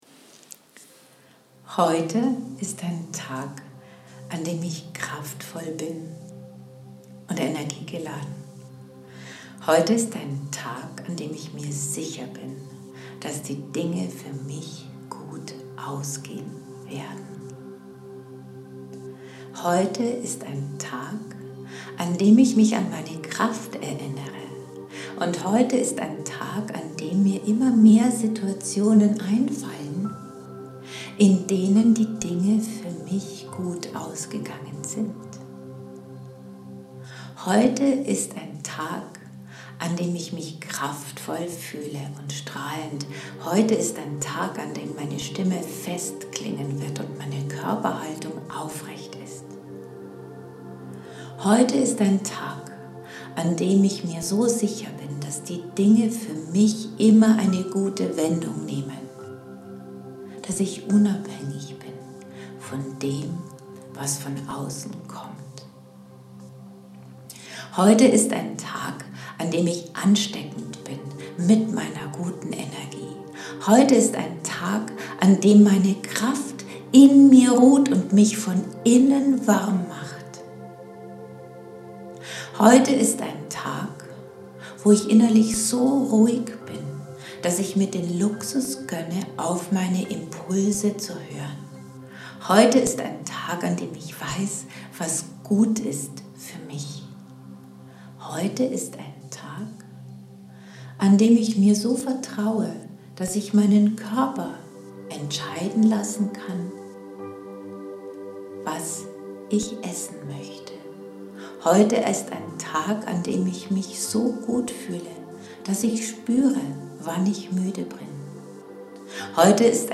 Eine sogannente „Affirmations-Dusche“ zum Thema : „Die Dinge werden heute gut für mich ausgehen.“